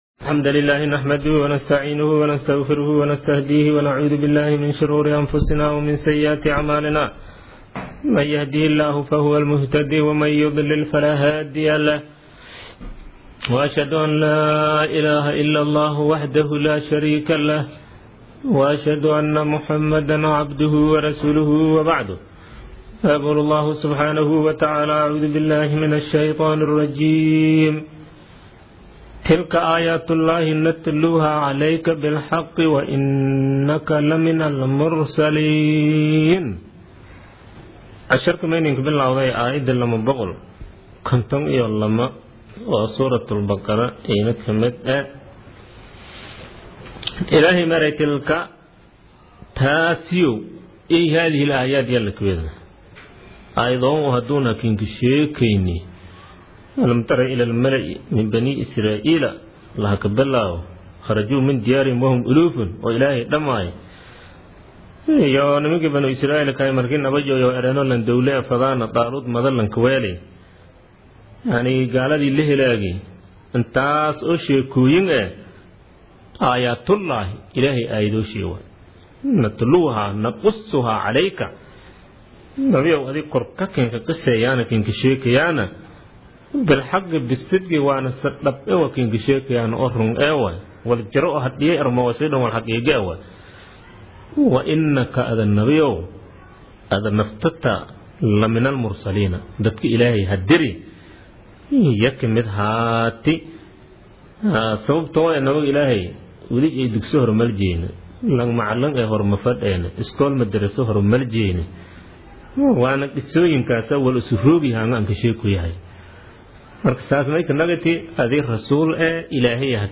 Casharka Tafsiirka Maay 33aad
Casharka-Tafsiirka-Maay-33aad.mp3